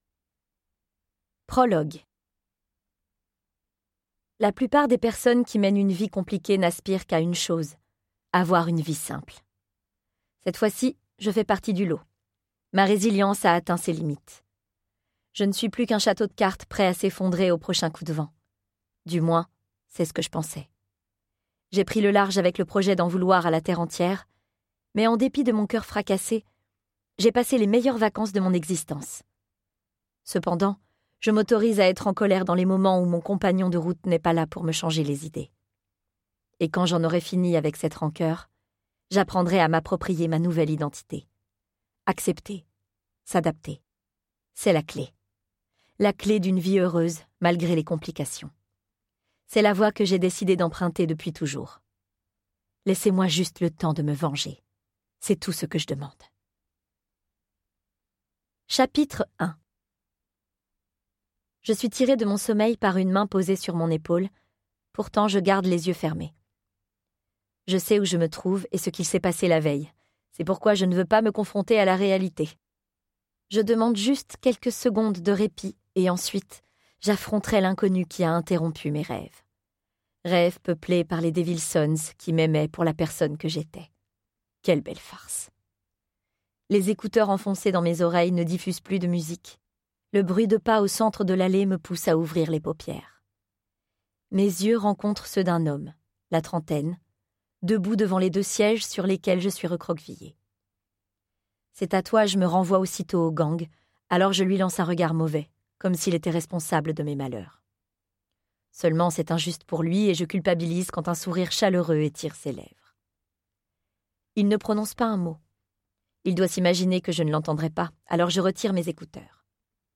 Click for an excerpt - The Devil's Sons, Tome 2 de Chloé Wallerand